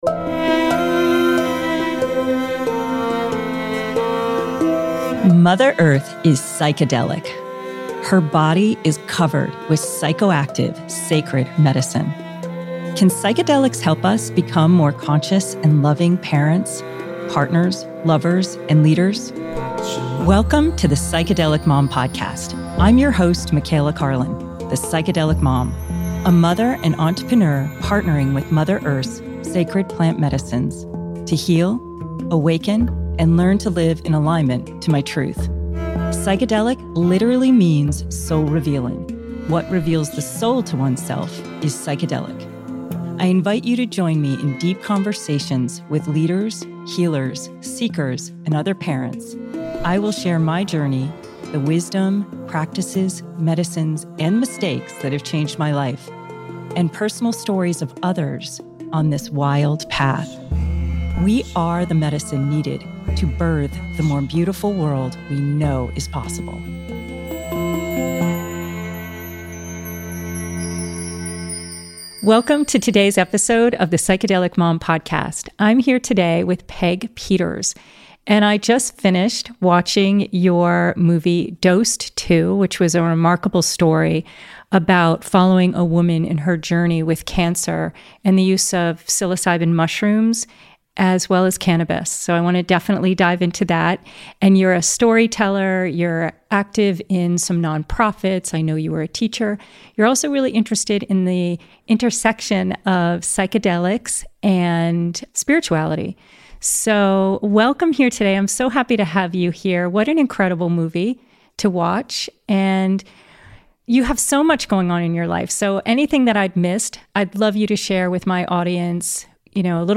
A Guided Meditation